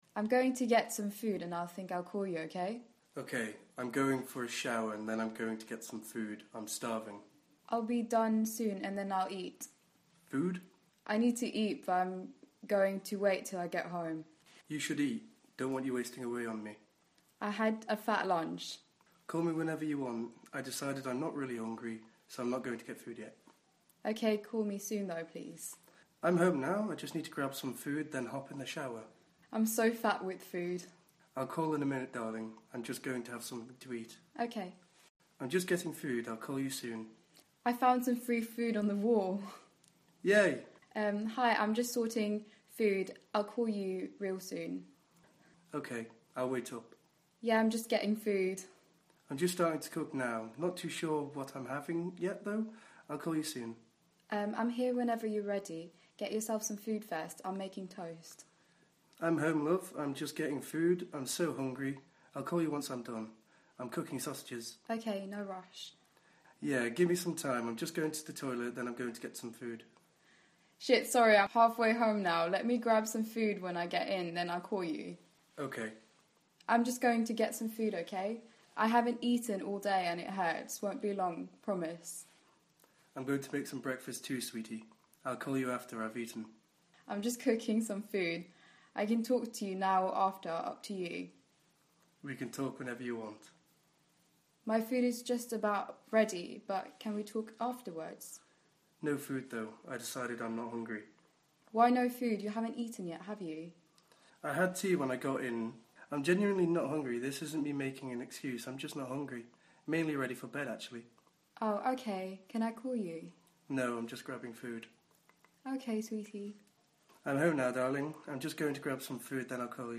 Selected excerpts from 'Do I Know You?' read aloud by actors. Dual-channel audio.